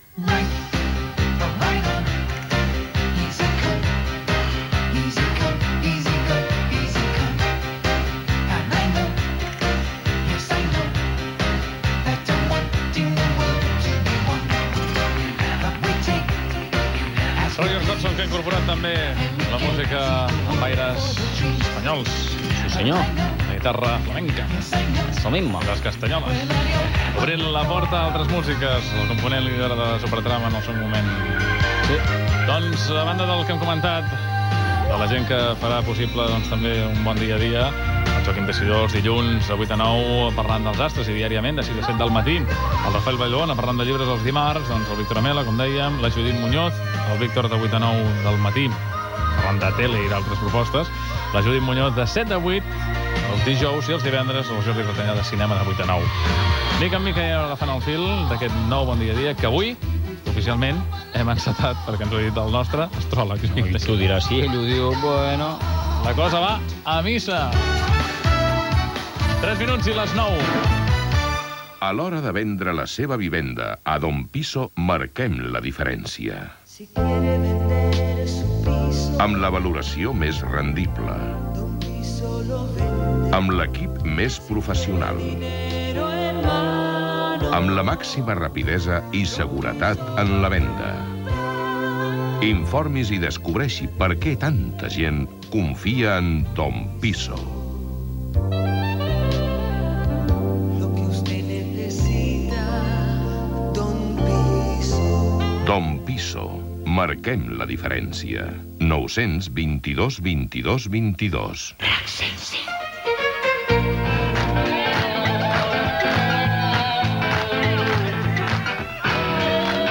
Equip del programa, hora, publicitat (veu Constantino Romero), indicatiu del programa, tema musical, comentari sobre el tema que ha sonat, hora
Musical
FM